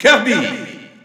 The announcer saying Kirby's name in French.
Kirby_French_Announcer_SSBU.wav